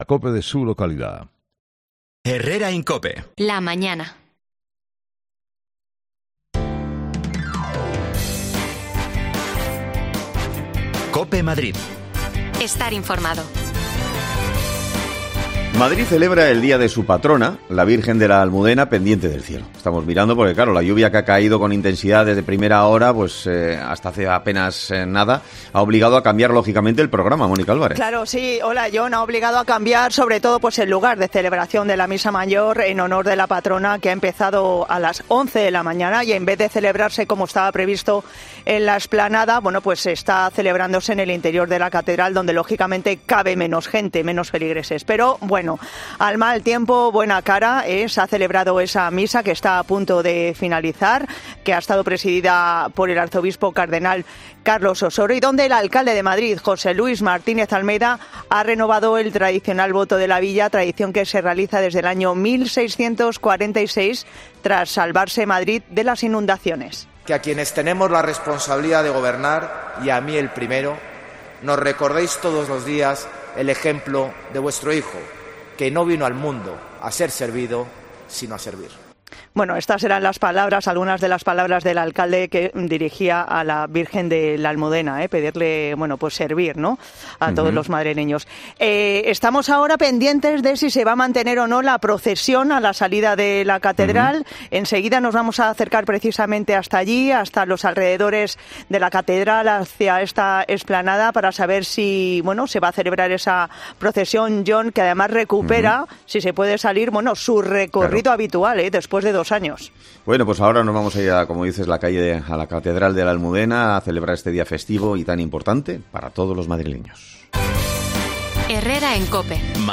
AUDIO: Madrid celebra este miércoles, el dia de su Patrona, la VIrgen de la Almudena, a pesar de la lluvia. Salimos a la calle para vivir esta fiesta
Las desconexiones locales de Madrid son espacios de 10 minutos de duración que se emiten en COPE , de lunes a viernes.